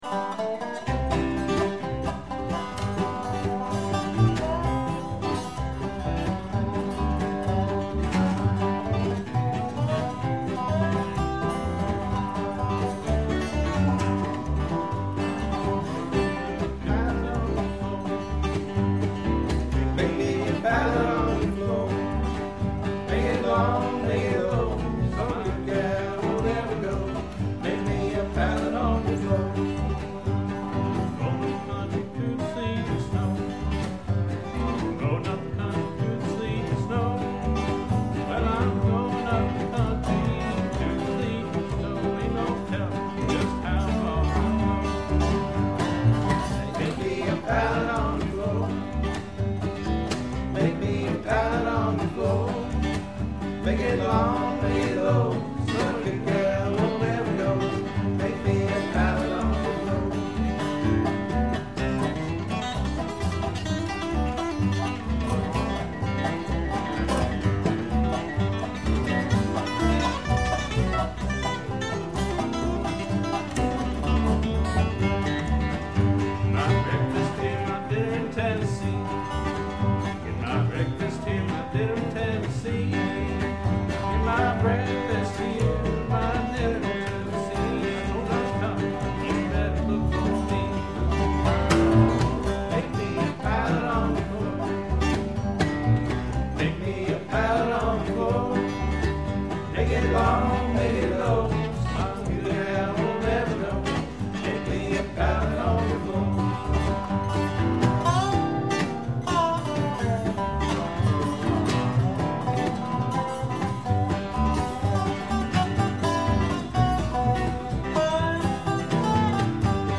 Bass
Dobro
Guitar